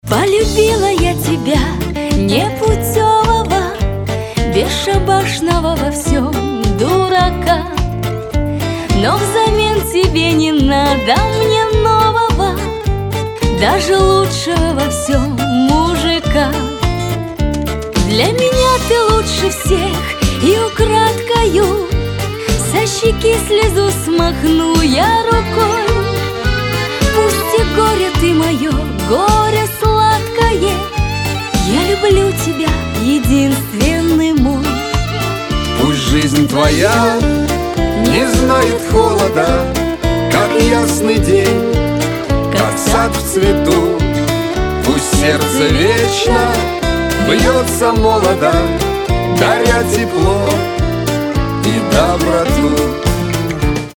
Категория: Нарезки шансона